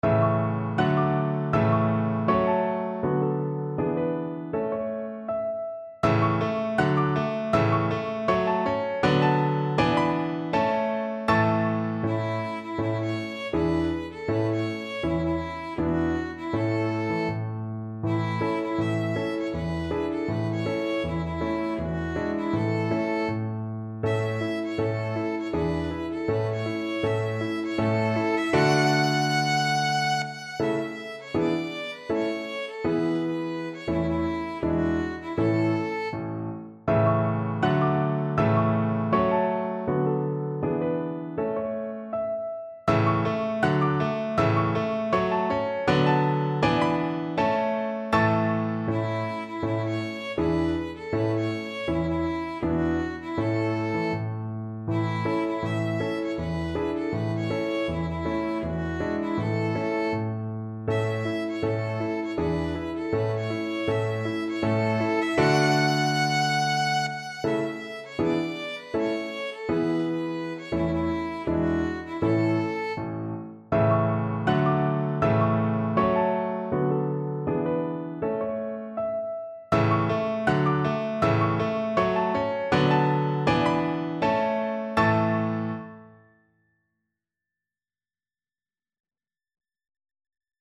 Violin version
2/4 (View more 2/4 Music)
Andante